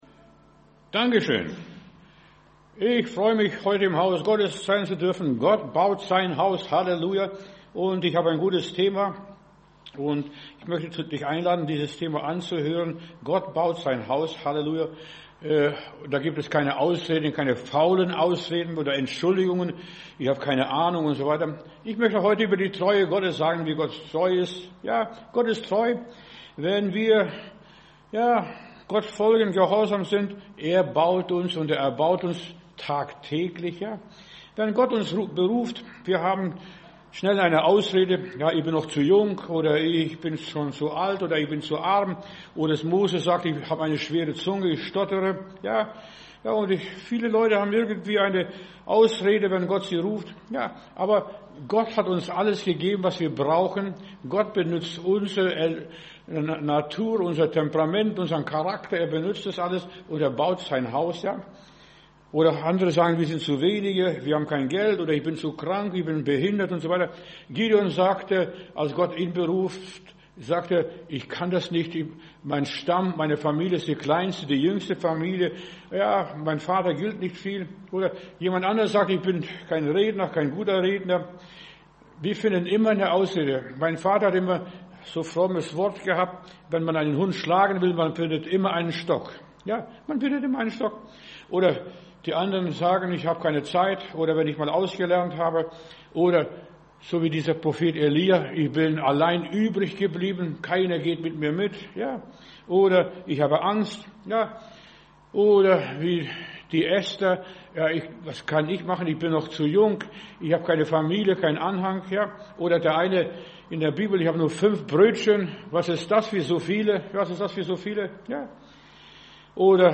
Predigt herunterladen: Audio 2026-01-21 Keine Ausrede Video Keine Ausrede